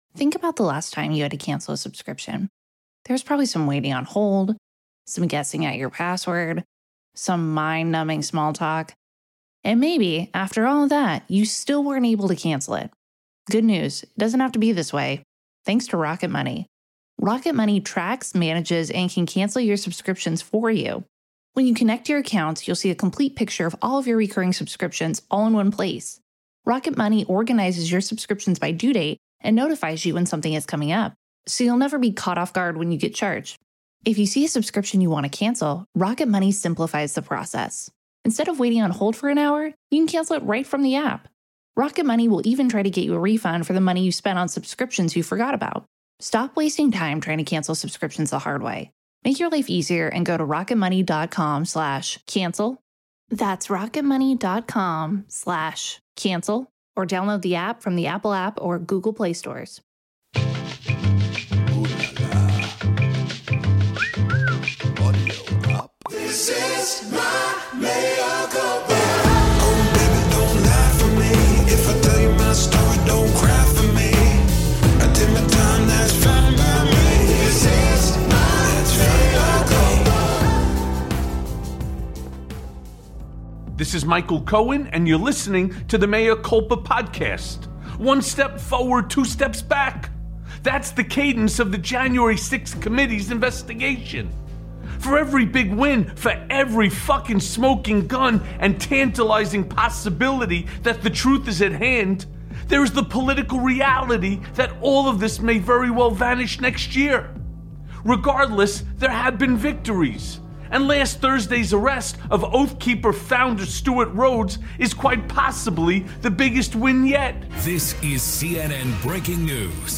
Inside the Oath Keepers Terrifying Jan 6th Plot+ A Conversation with Rick Wilson
Michael breaks down the Oath Keepers January 6th plot and how it was far worse than anybody realized. The Lincoln Project's Rick Wilson joins Mea Culpa to tell us what it all means.